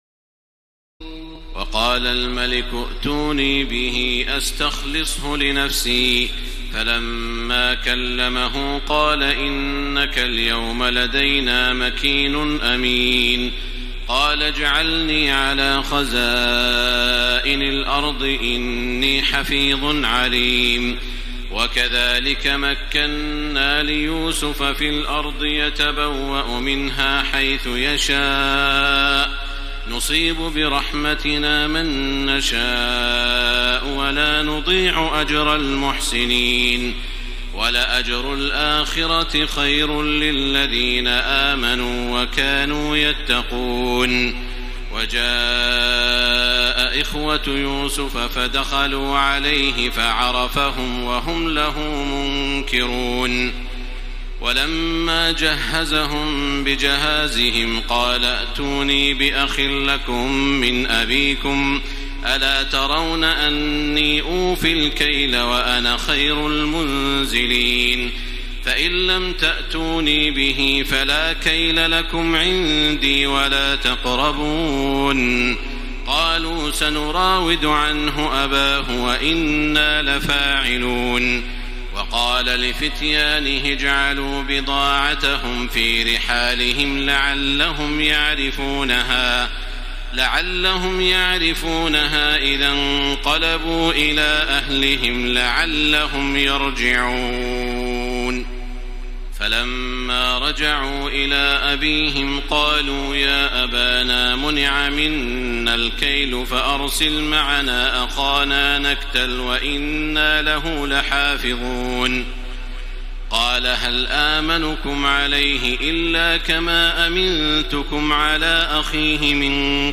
تراويح الليلة الثانية عشر رمضان 1434هـ من سورتي يوسف (54-111) و الرعد (1-18) Taraweeh 12 st night Ramadan 1434H from Surah Yusuf and Ar-Ra'd > تراويح الحرم المكي عام 1434 🕋 > التراويح - تلاوات الحرمين